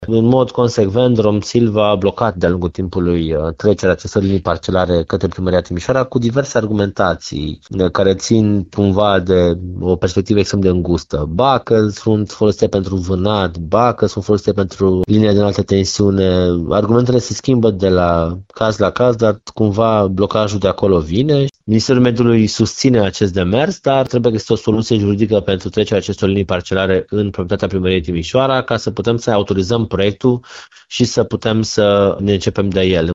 Viceprimarul Ruben Lațcău susține că, în timp ce proiectul este aproape finalizat, Romsilva refuză să predea municipalității drumurile și liniile parcelare fără de care nu pot fi executate lucrările.